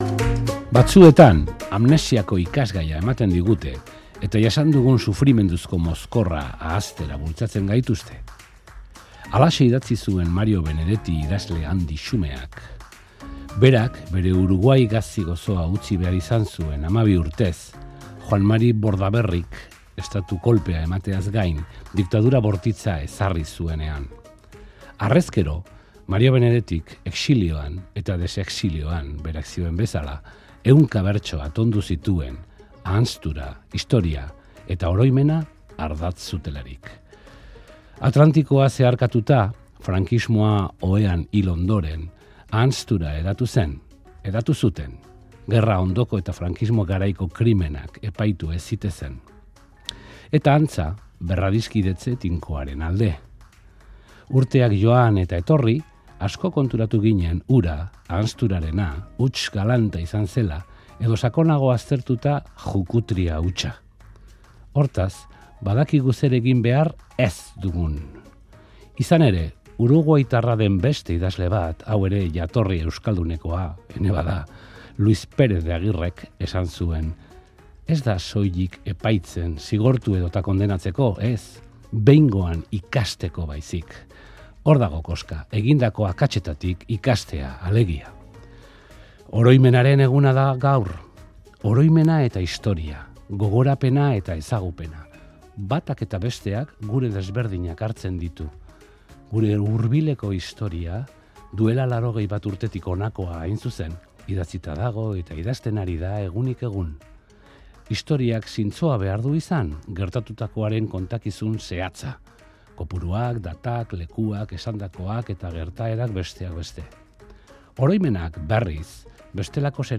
iritzi-jarduna